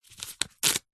Звуки билета
Звук проверки билета и отрыв части по надрезам